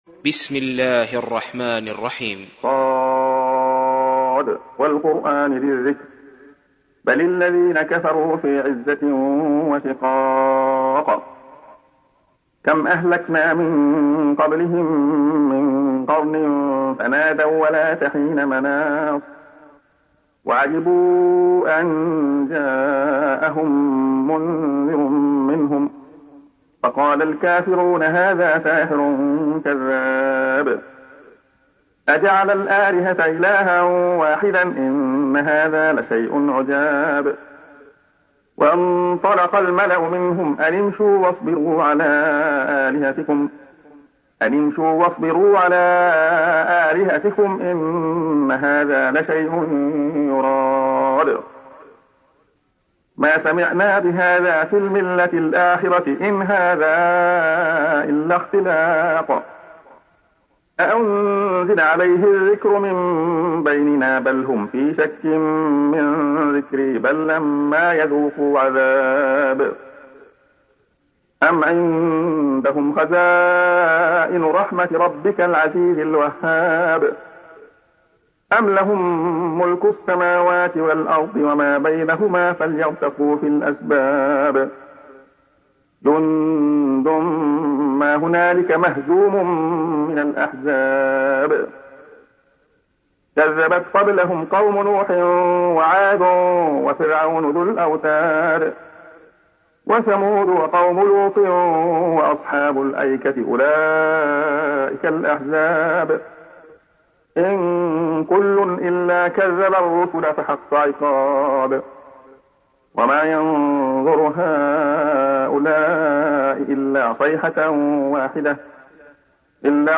تحميل : 38. سورة ص / القارئ عبد الله خياط / القرآن الكريم / موقع يا حسين